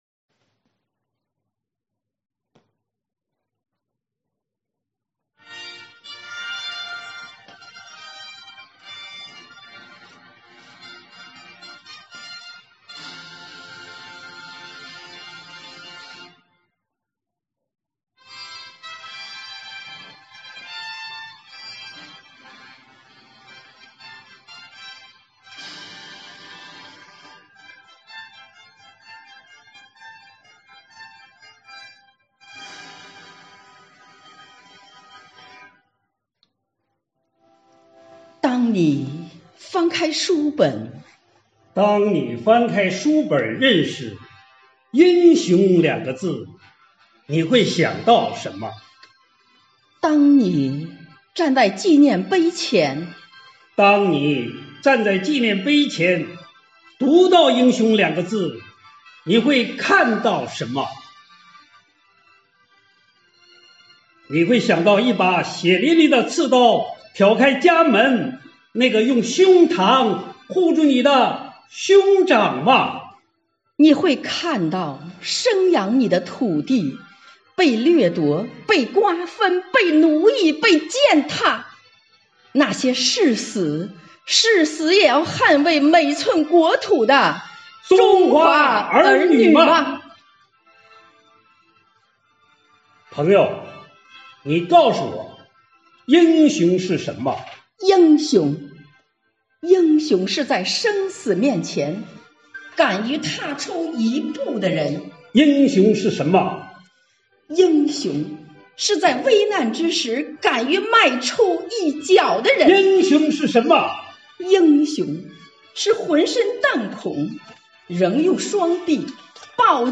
合诵